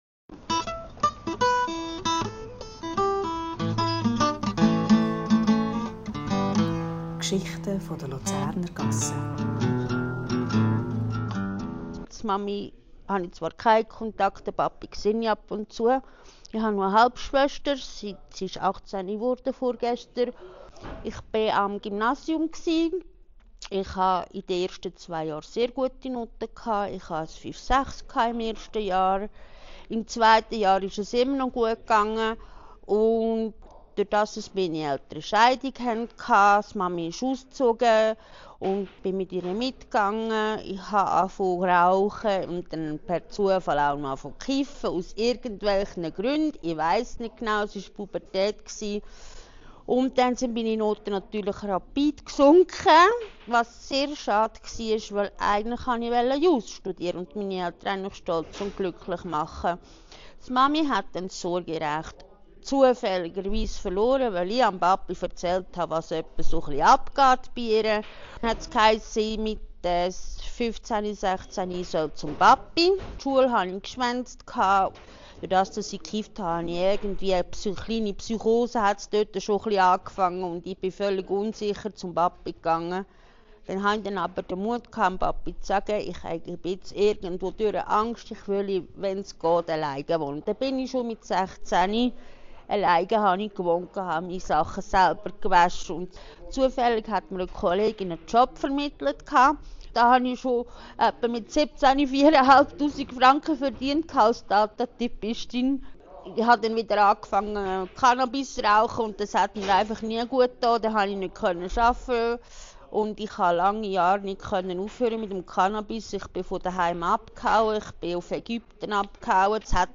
/ aufgenommen in der Gassechuchi am 27. Januar 2026.